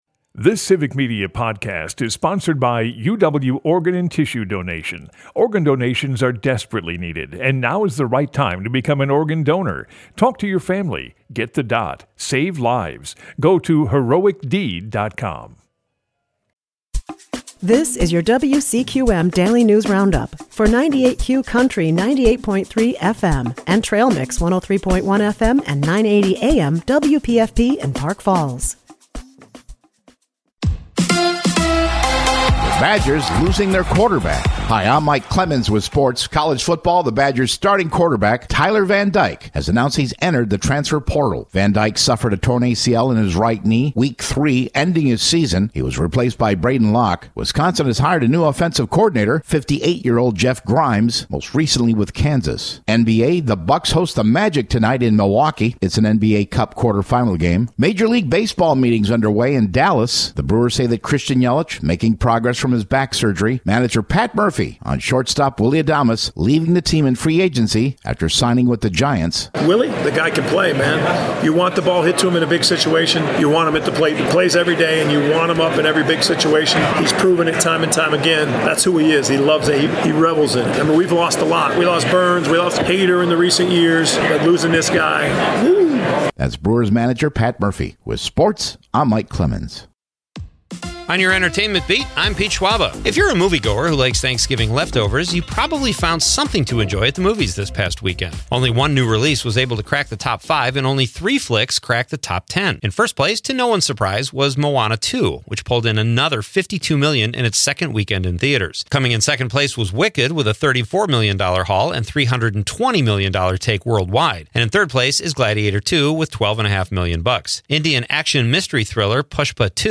wcqm news